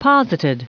Prononciation du mot posited en anglais (fichier audio)
Prononciation du mot : posited